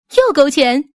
right-hook.mp3